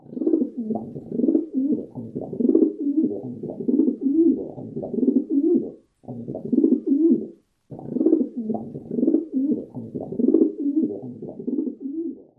岩鸽“咕咕”叫声